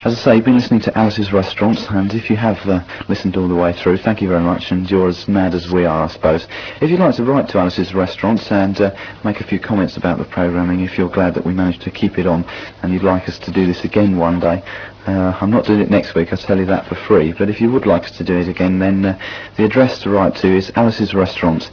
Here you can listen to off air and studio recordings of landbased pirate radio stations, they feature stations based in London and the home counties from the late 70's to almost the present day